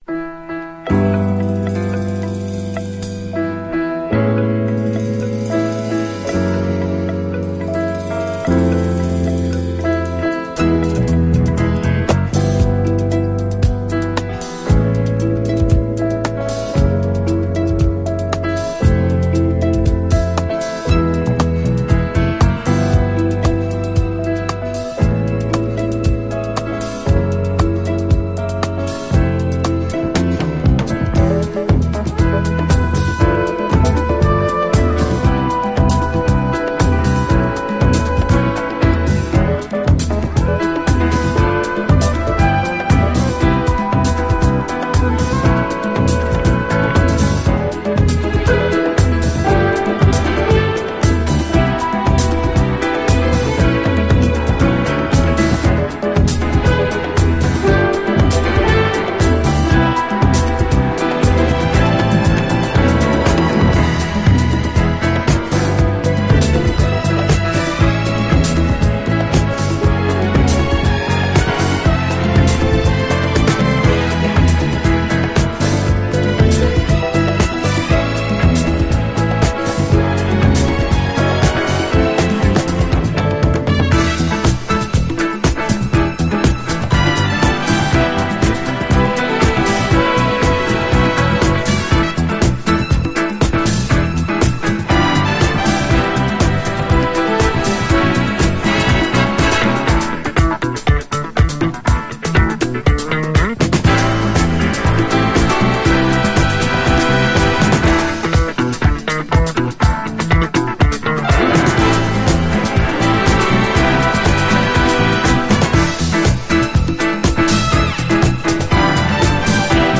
ゴージャスなブラスや女性コーラス隊の入り方など、アレンジがキラリと光るお洒落モダン・ソウル